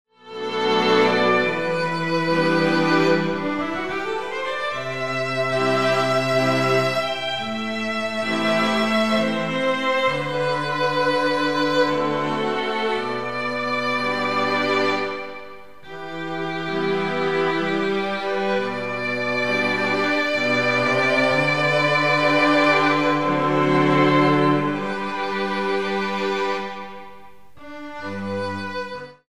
A Modern Dance Choreography CD
18 Instrumental Compositions  /  various orchestrated moods.